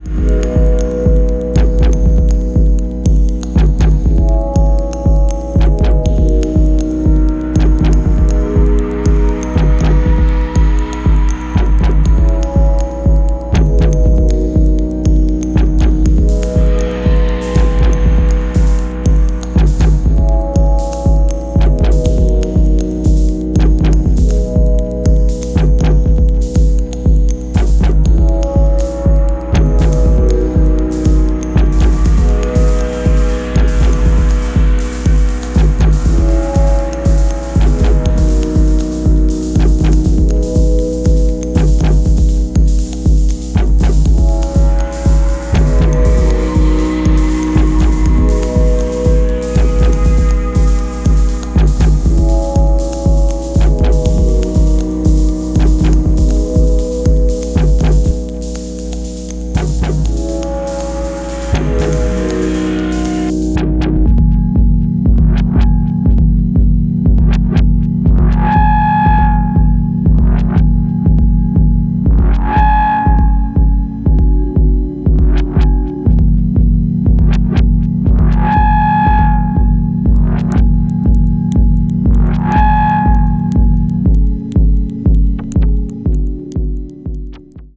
creates in his small homestudio in Deventer (NL)
Electronix House Techno Ambient